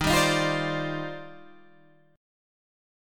D#M13 chord